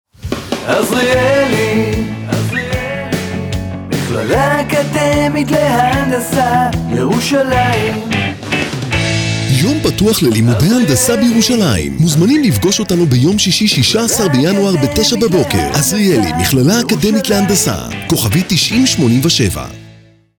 תשדירי רדיו לדוגמה